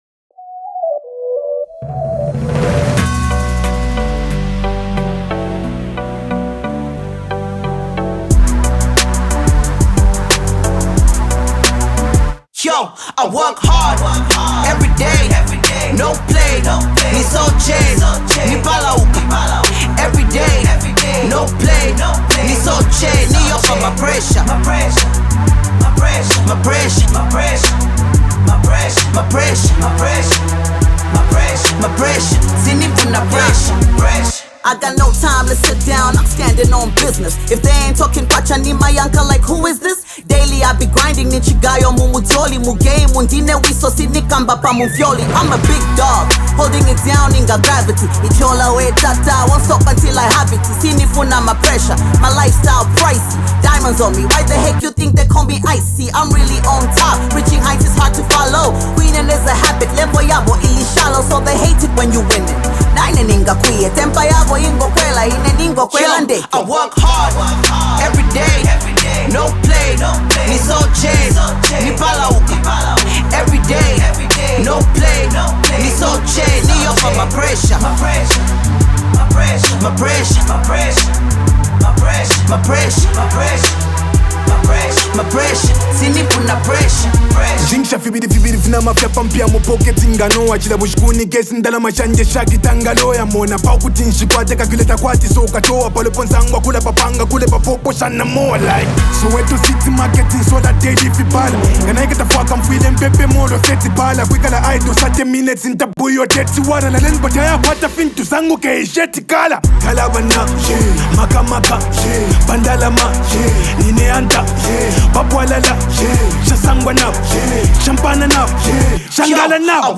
Zambian hip-hop